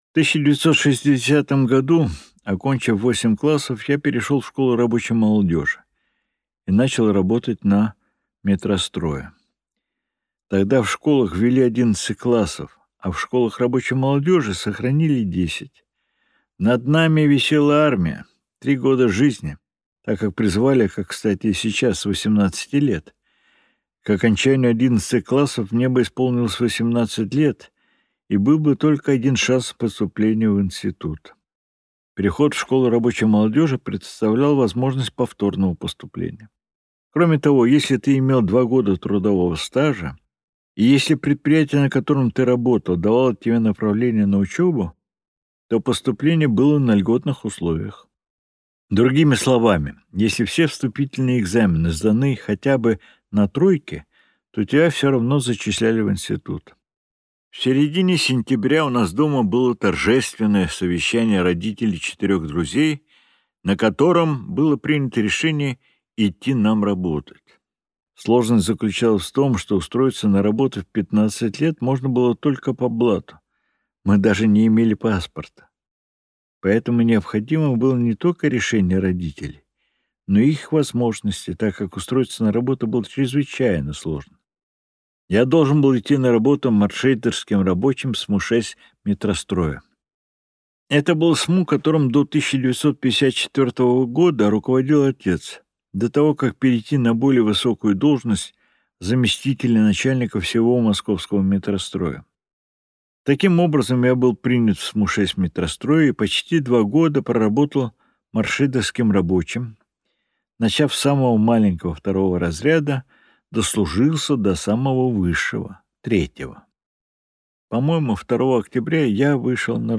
Аудиокнига Почти книга | Библиотека аудиокниг